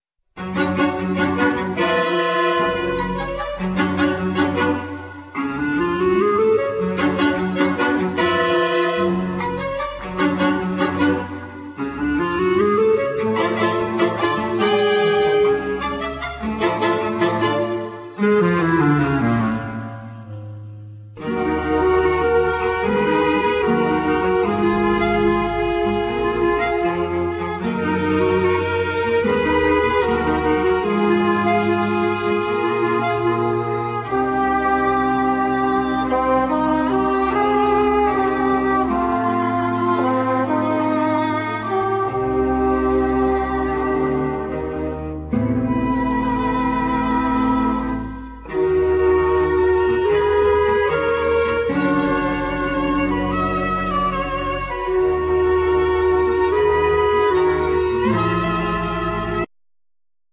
Clarinet
Keyboards,Short Waves
Cello
Percussions
Chapman Stick
Violin
Viola
French Horn
Guitar